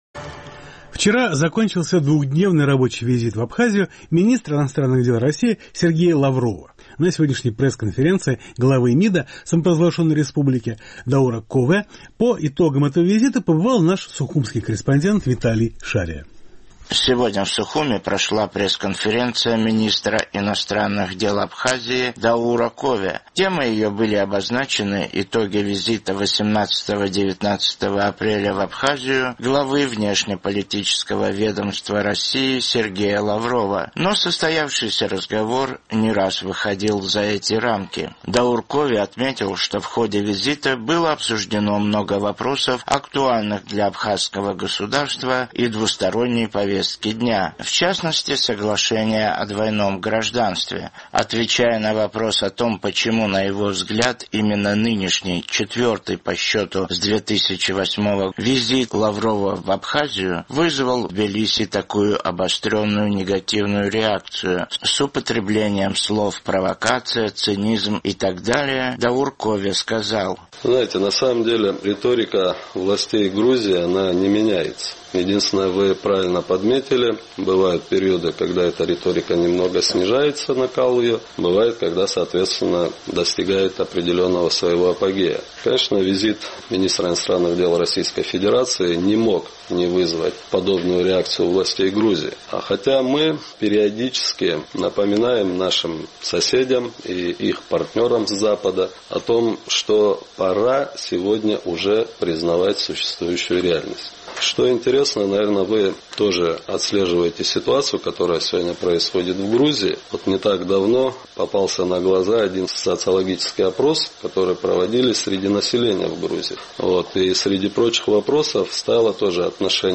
Сегодня в Сухуме прошла пресс-конференция министра иностранных дел Абхазии Даура Кове. Темой ее были обозначены итоги визита 18-19 апреля в Абхазию главы внешнеполитического ведомства России Сергея Лаврова.